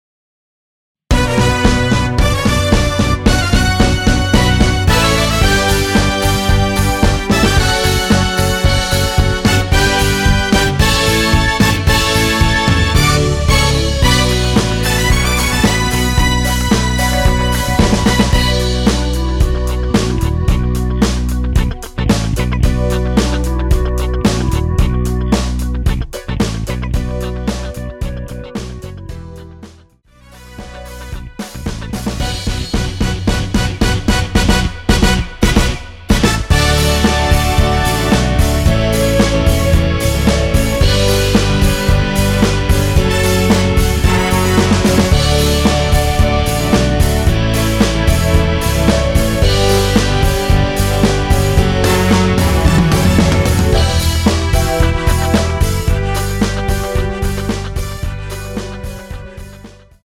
원키에서(-8)내린 멜로디 포함된 MR입니다.(미리듣기 확인)
Bm
앞부분30초, 뒷부분30초씩 편집해서 올려 드리고 있습니다.